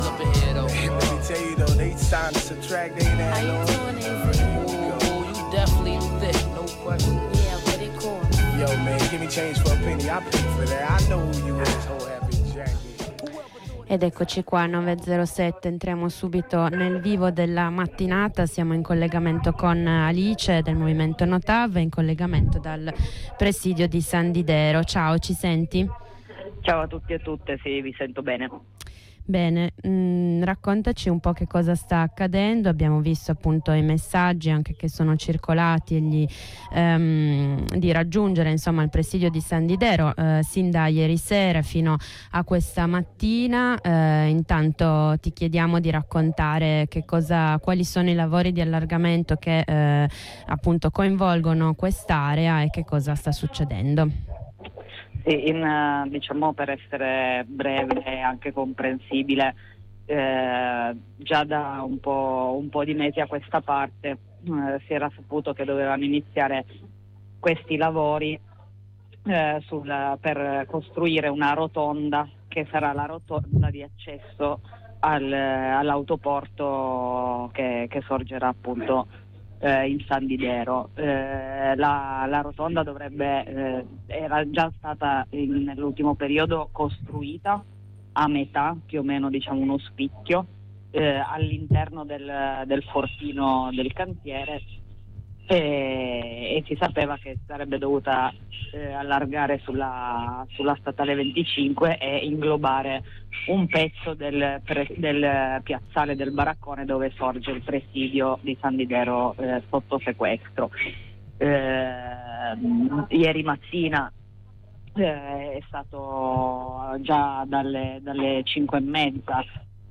Ai nostri microfoni l’aggiornamento